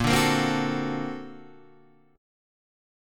A#+M9 chord